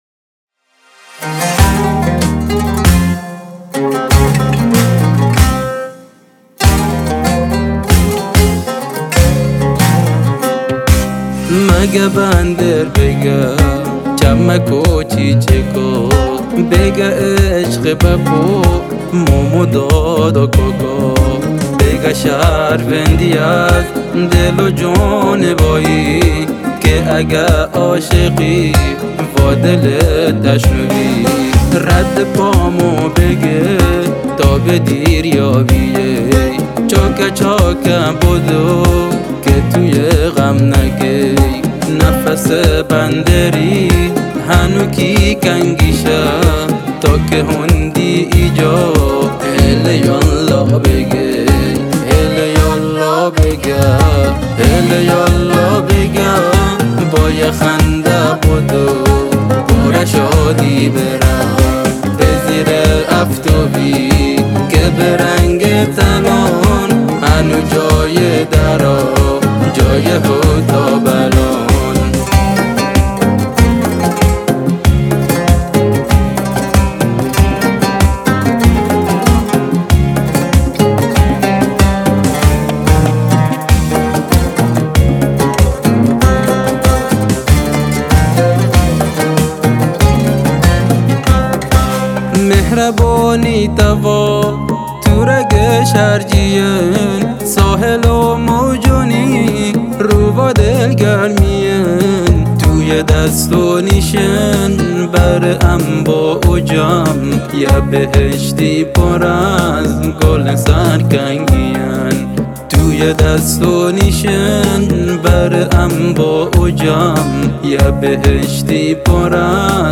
• دهل و کسر
• جفتی
• بک وکال